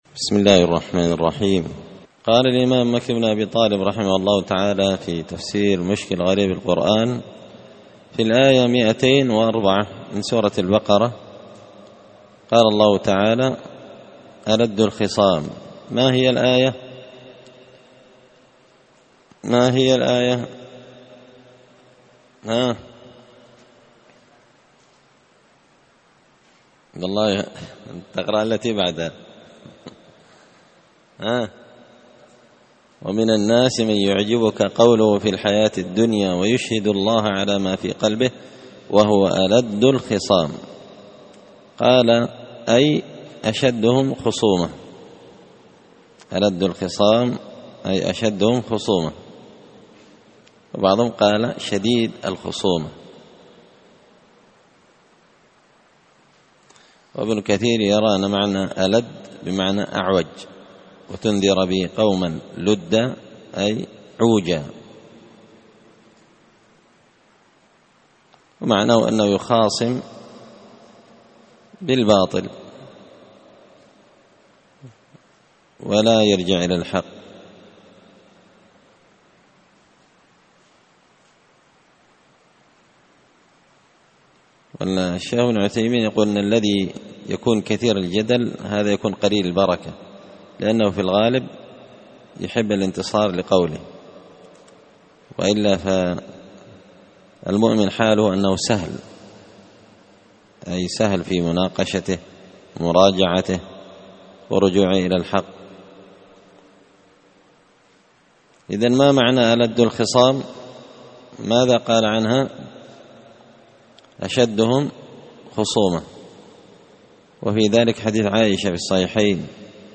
تفسير مشكل غريب القرآن ـ الدرس 38
دار الحديث بمسجد الفرقان ـ قشن ـ المهرة ـ اليمن